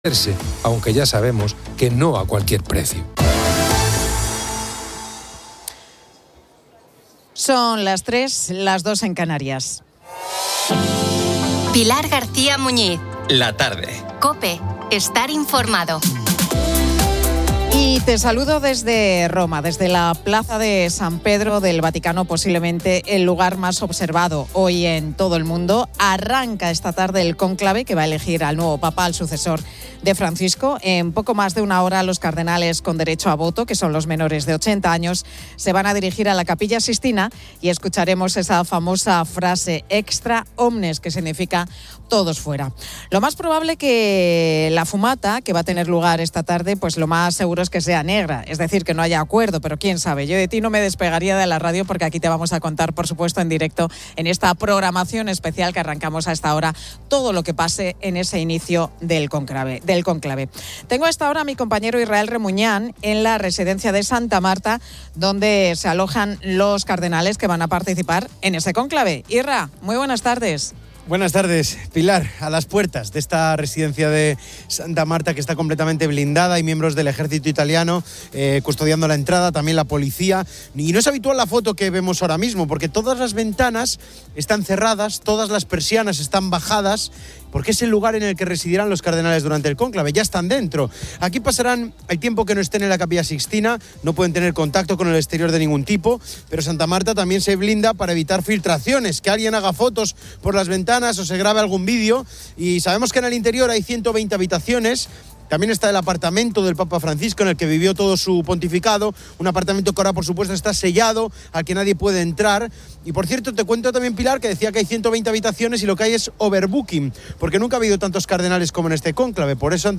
Y te saludo desde Roma, desde la Plaza de San Pedro del Vaticano, posiblemente el lugar más observado hoy en todo el mundo. Arranca esta tarde el cónclave que va a elegir al nuevo Papa, al sucesor de Francisco.